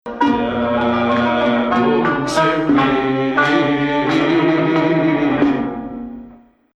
Rast 1